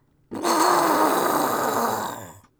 zombie_agressive_044.wav